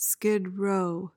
PRONUNCIATION: (skid roh) MEANING: noun: An area where people down on their luck congregate, often struggling with poverty, addiction, or homelessness.